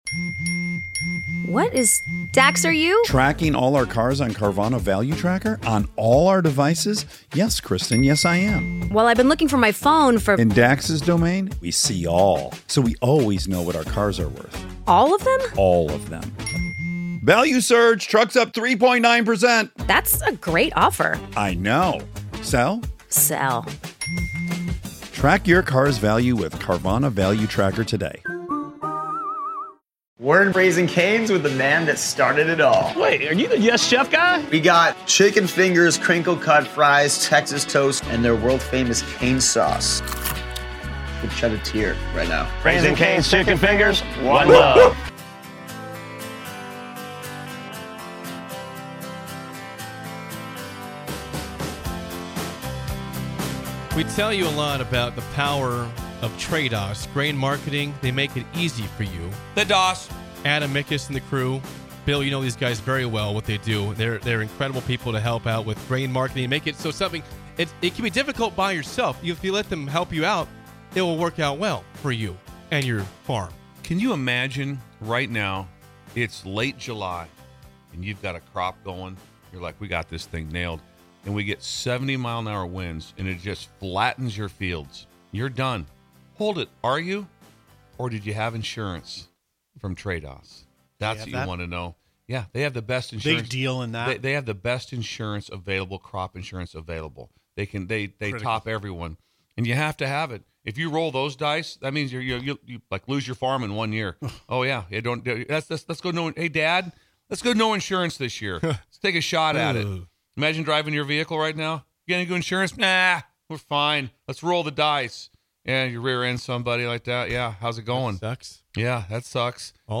Join these three goofballs from 6-9am every weekday morning for the most upbeat and energetic morning show you'll ever experience. Grab a cup of coffee, turn up the volume, and imagine you're right alongside them in studio!!